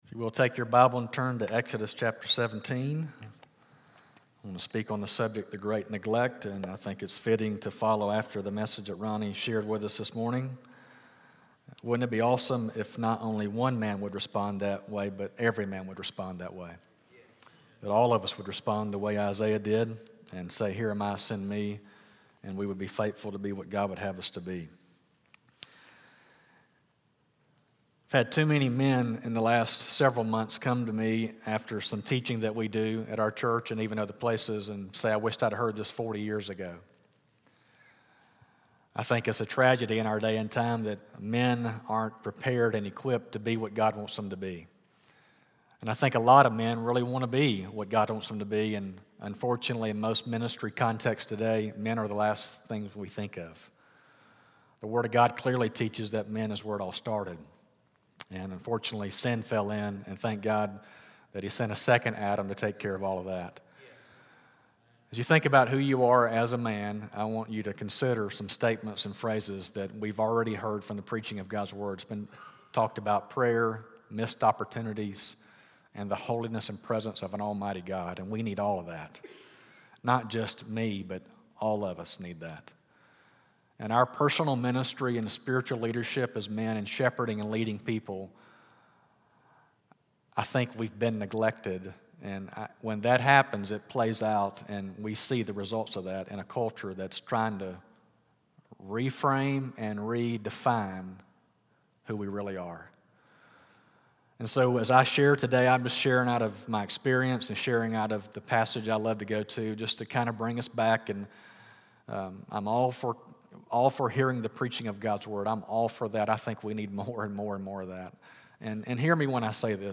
From the morning session of the Real Momentum Conference on Saturday, August 3, 2019